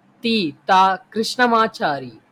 த. கிருஷ்ணமாச்சாரி.wav Audioaufzeichnung der Aussprache eines Begriffs. Sprache InfoField Tamil Transkription InfoField தி. த. கிருஷ்ணமாச்சாரி Datum 1.